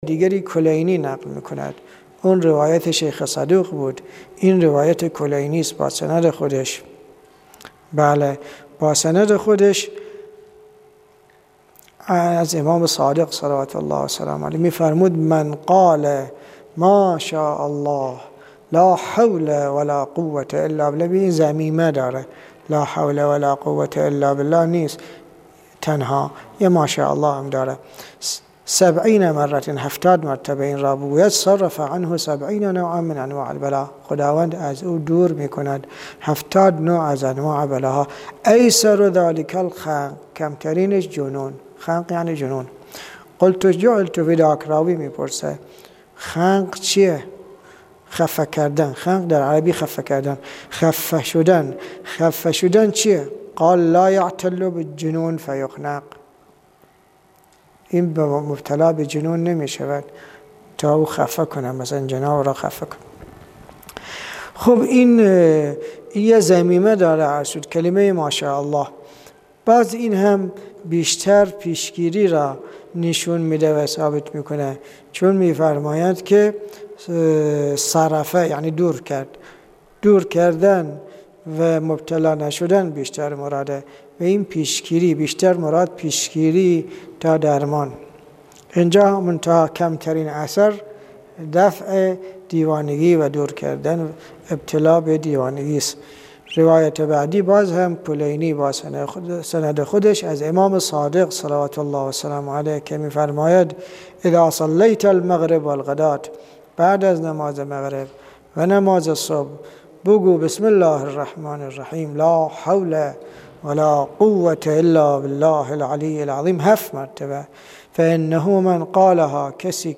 صوت تدریس کتاب الدراسة فی الطب